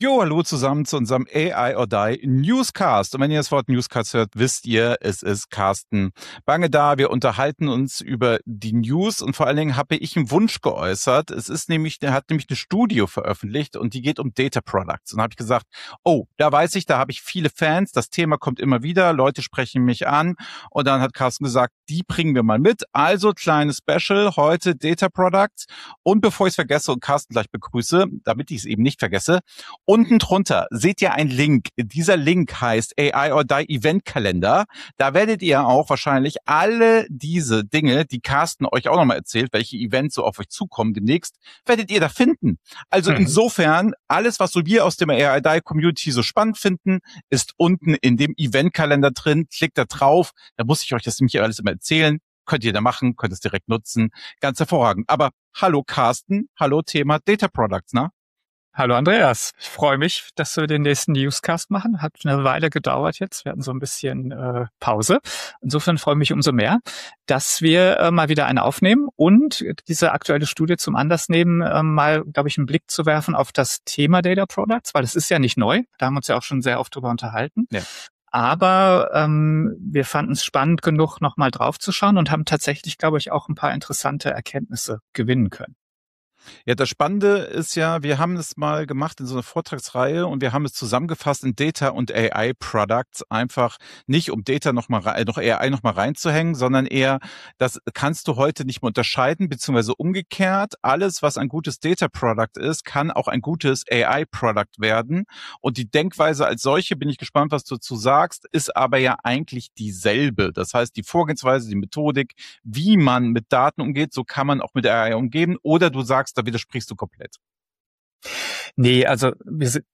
00:00 – Intro: Newscast und Fokus auf Data Products